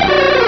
Cri de Caratroc dans Pokémon Rubis et Saphir.